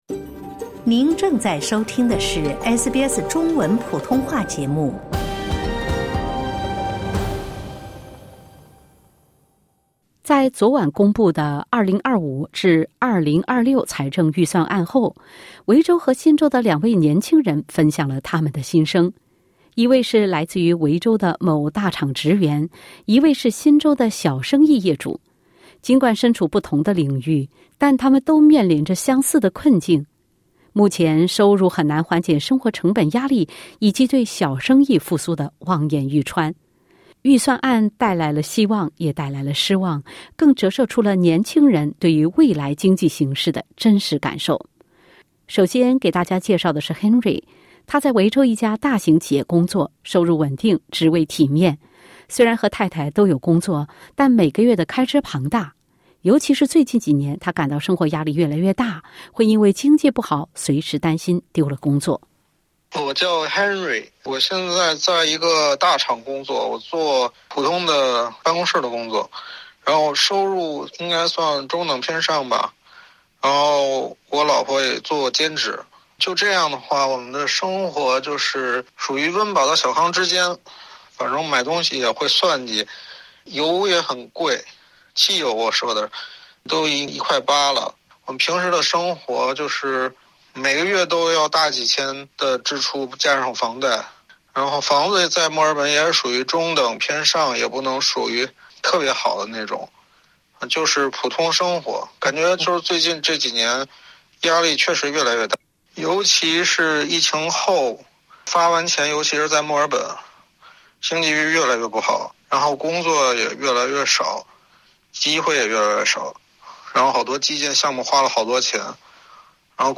维州、新州两位华人青年分享自己当下生存状态及生活压力。